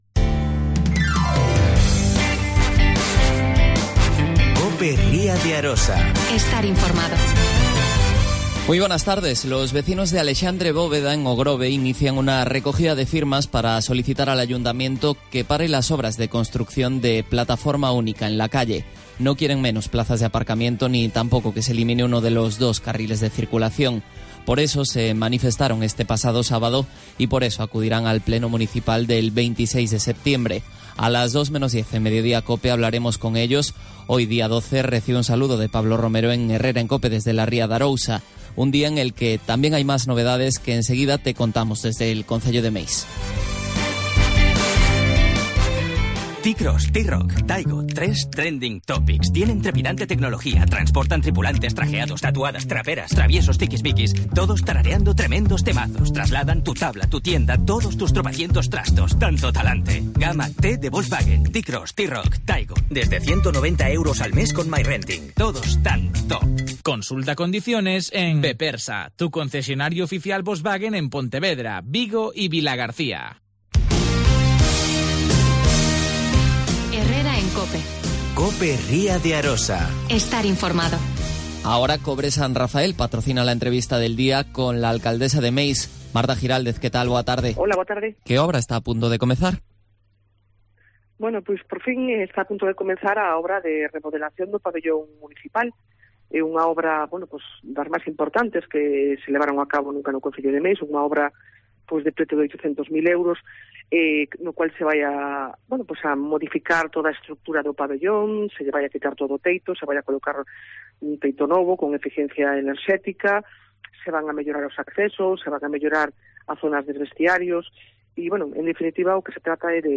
AUDIO: Marta Giráldez. Alcaldesa de Meis.